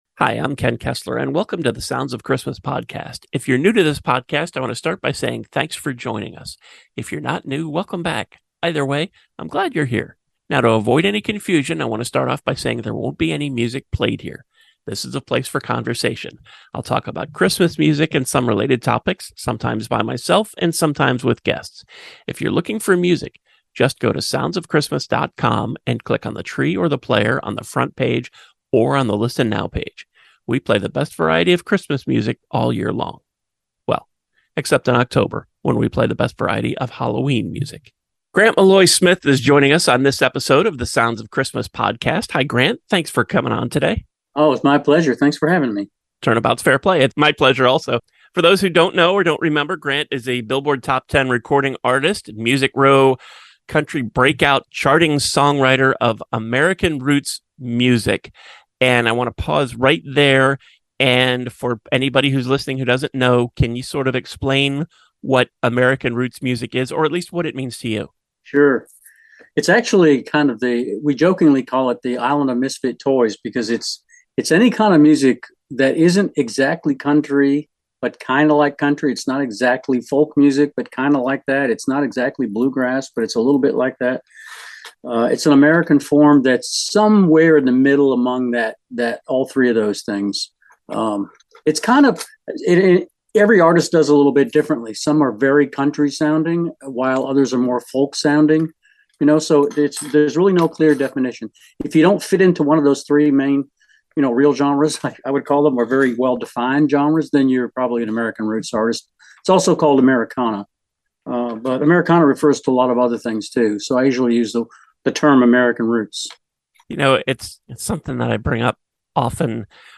You can watch it here: Next up is the Christmas joke and the less said about that the better.